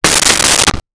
Toilet Seat Fall 01
Toilet Seat Fall 01.wav